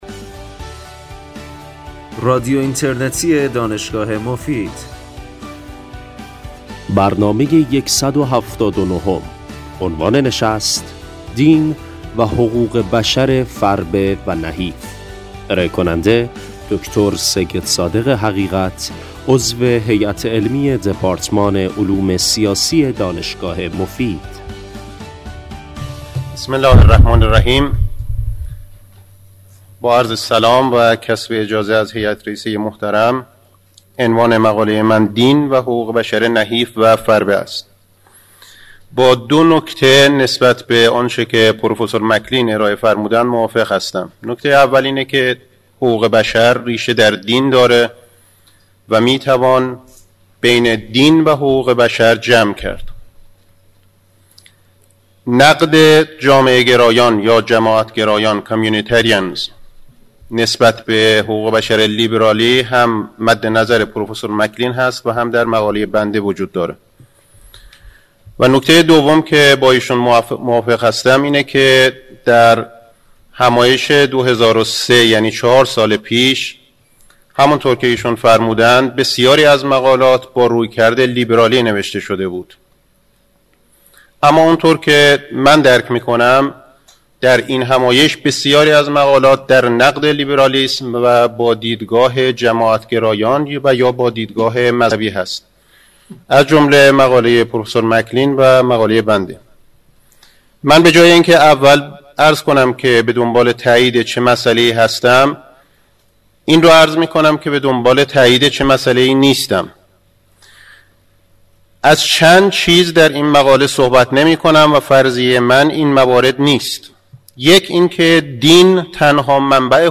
در پایان برنامه نیز سوالاتی از سوی حضار طرح می‌گردد که ایشان پاسخ می‌گویند.